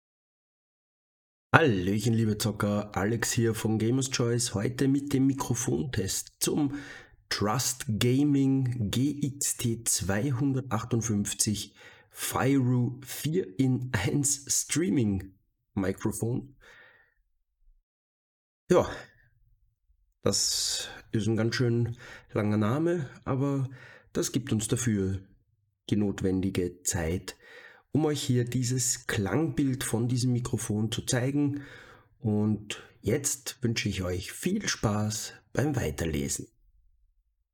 Habt ihr diese Hürde der Lautstärke aber überwunden, dann klingt es echt spitze, aber am Besten ihr hört mal selbst!
Wie ihr nun hören konntet klingt das Mikrofon wirklich sehr klar und durch den im Mikrofon verbauten Pop-Filter gibt es kaum verfälschte Töne!
Trust-Fyru-Mic-Test.mp3